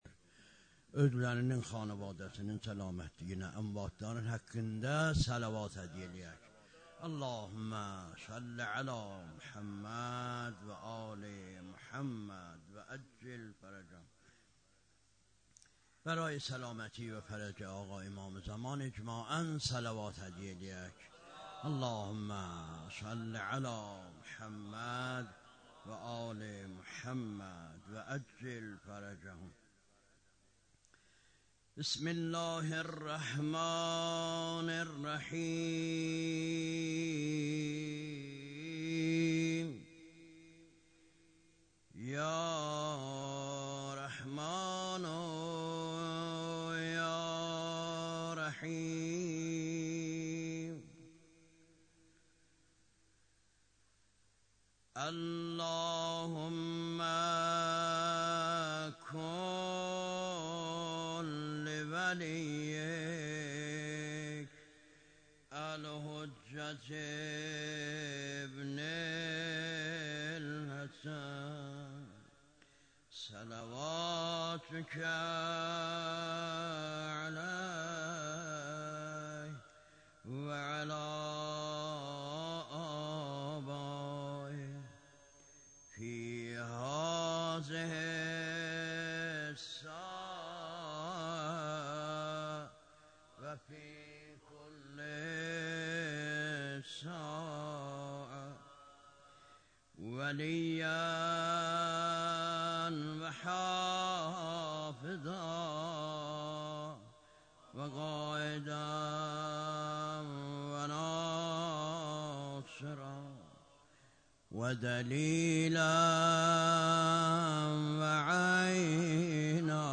خیمه گاه - هیئت حضرت رقیه س (نازی آباد) - شب ششم مداحی
محرم سال 1398